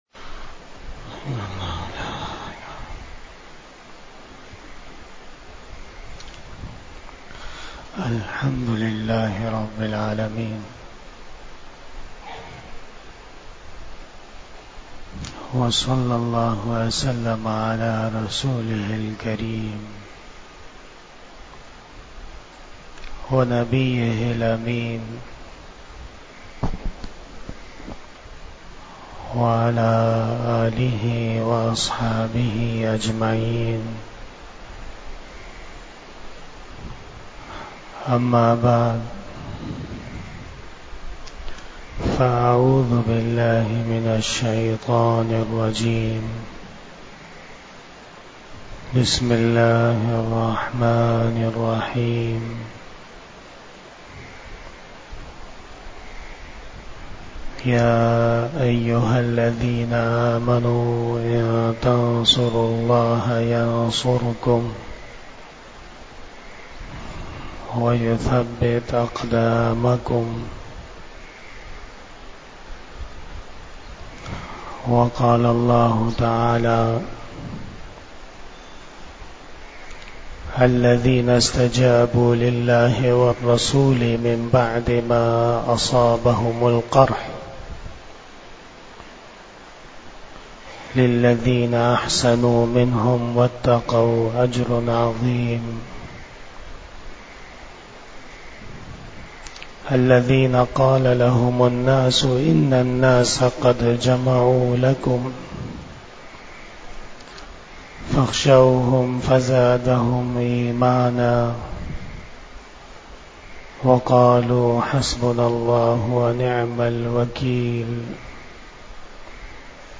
41 Bayan E Jummah 13 October 2023 (26 Rabi Ul Awwal 1445 HJ)
Khitab-e-Jummah